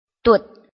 臺灣客語拼音學習網-客語聽讀拼-南四縣腔-入聲韻
拼音查詢：【南四縣腔】dud ~請點選不同聲調拼音聽聽看!(例字漢字部分屬參考性質)